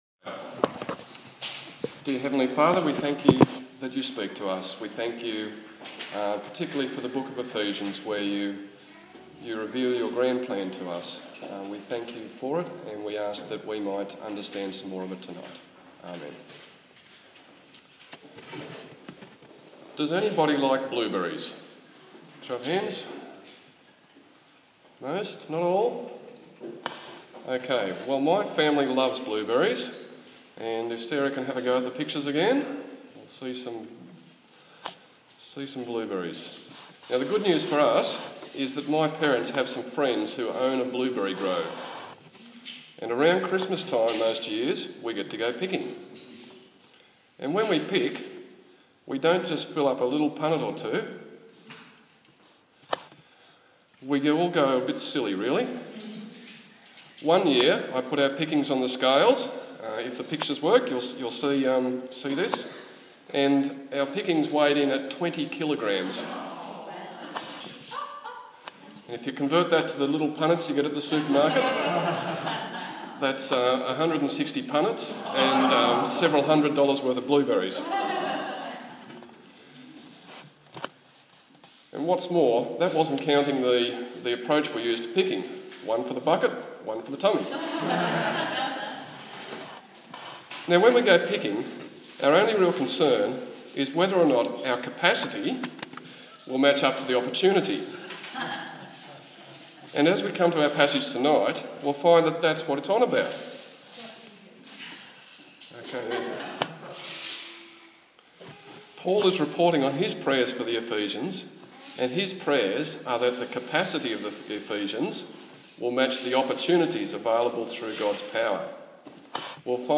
The sixth in the sermon series on Ephesians